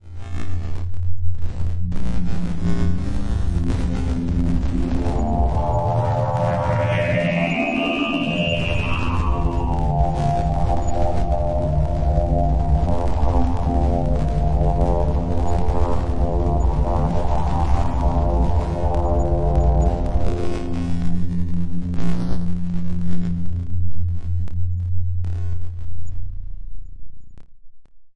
Tag: 电气 电子 出问题 sounddesign 平移 数字 声音效果 静态 无人驾驶飞机 脉冲 黑暗 扭曲 未来 处理 抽象的 sounddesign SFX 科幻 噪声